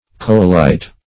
Coalite \Co"a*lite\, v. t.